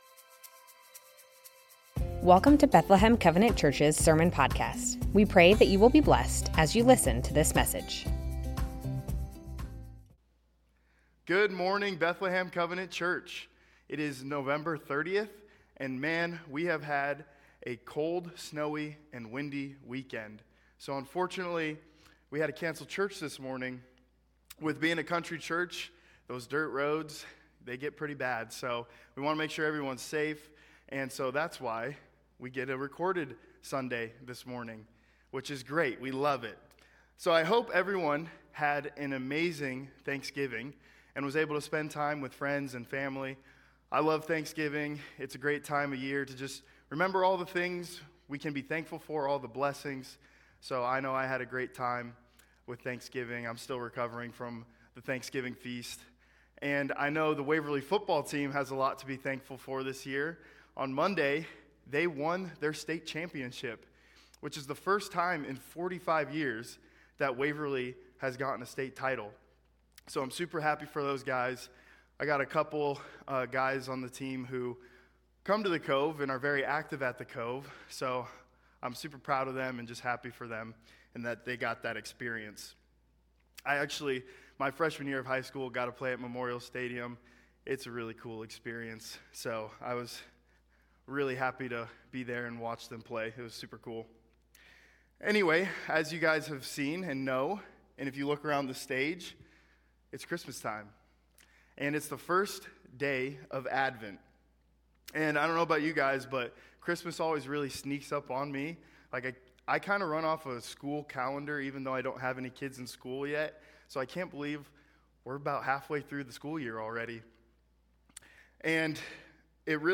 Bethlehem Covenant Church Sermons Advent - Hope Nov 30 2025 | 00:31:27 1x 00:00 / 00:31:27 Subscribe Share Spotify RSS Feed Share Link Embed